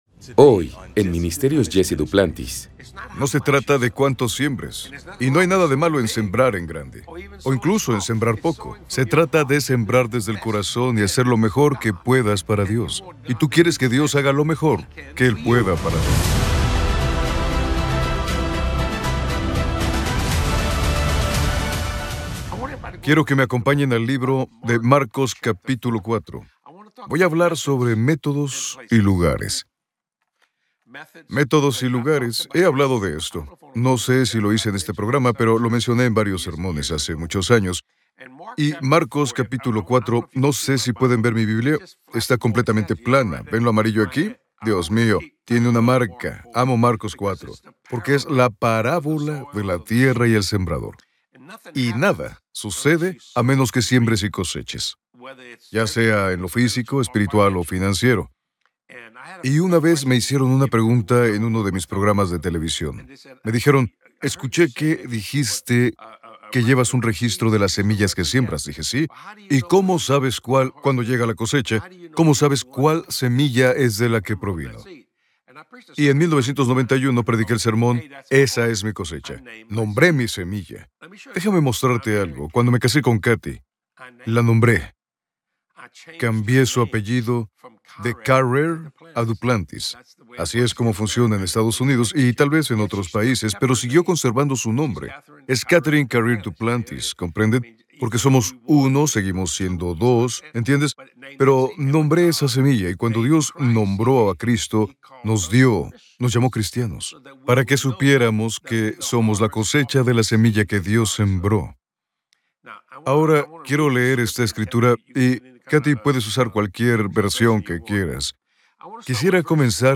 esclarecedora charla en la SALA DE JUNTAS